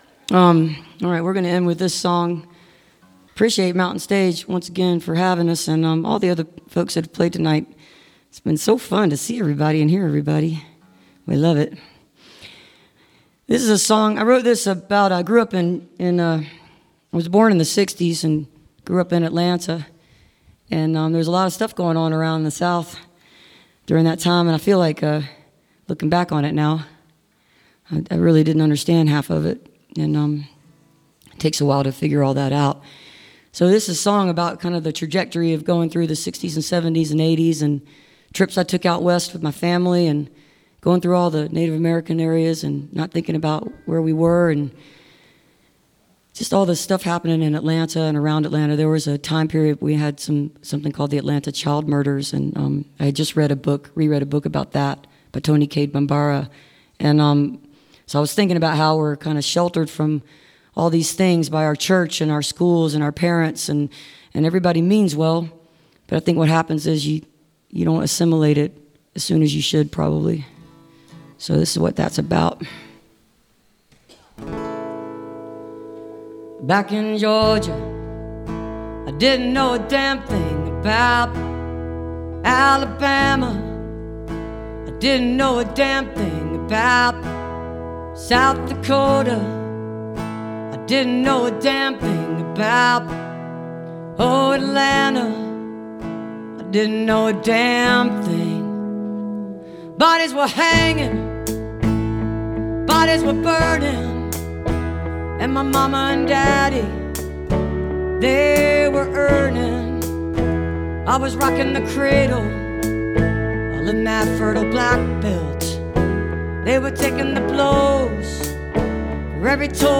(captured from a websteam)